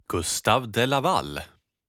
Karl Gustaf Patrik de Laval (Swedish pronunciation: [ˈɡɵ̂sːtav laˈvalː]